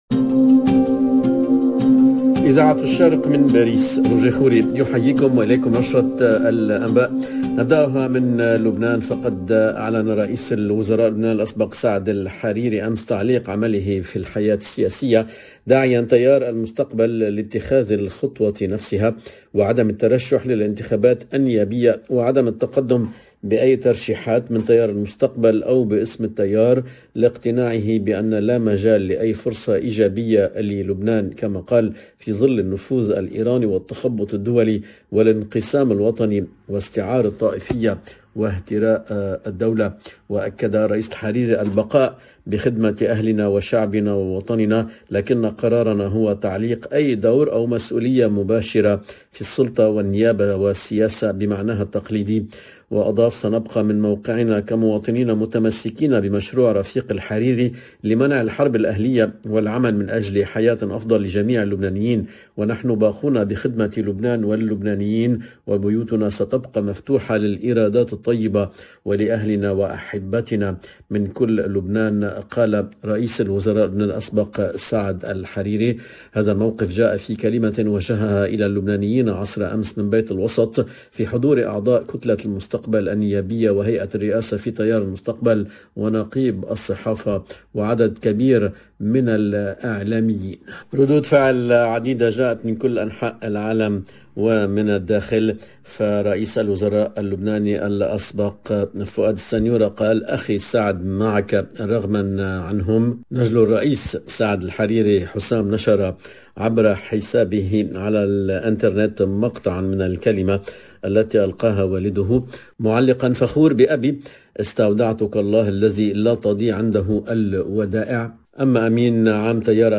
LE JOURNAL DE MIDI 30 EN LANGUE ARABE DU 25/01/22